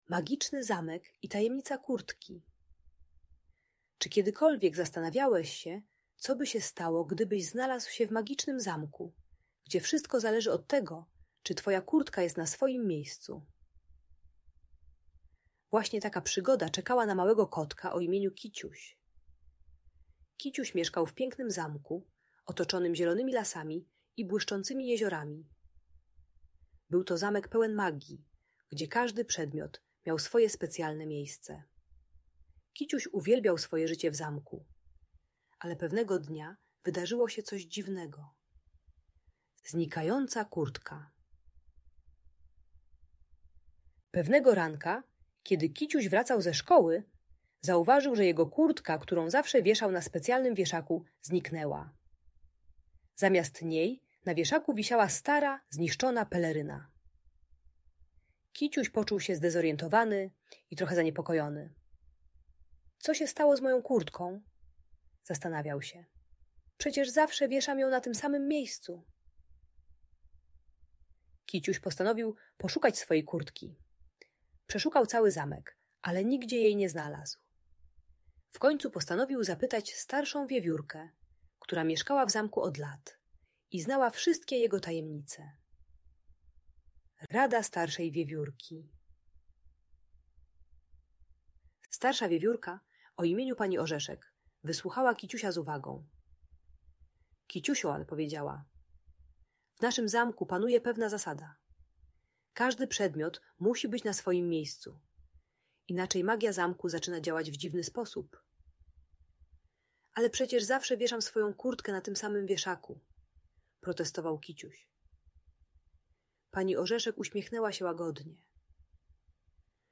Magiczny Zamek i Tajemnica Kurtki - Audiobajka dla dzieci